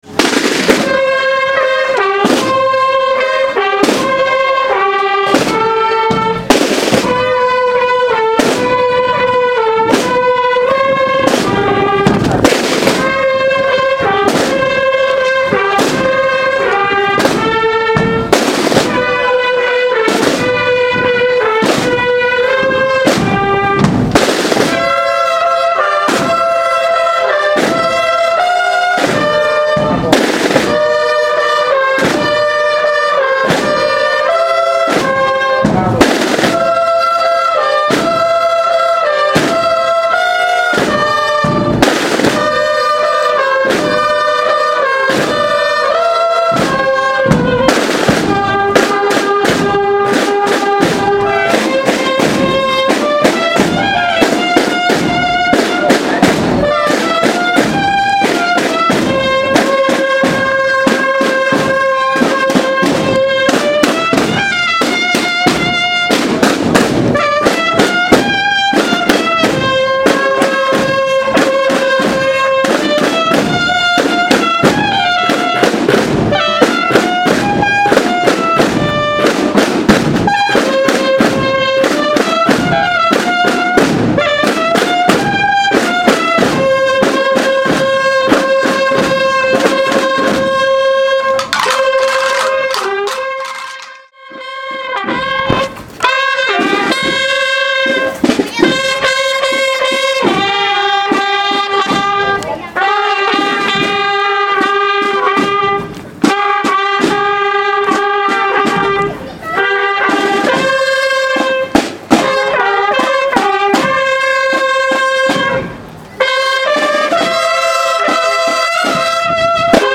Procesión Fiestas de Lébor 2016
Contó con la participación de la banda de cornetas y tambores de la Hermandad de la Negación.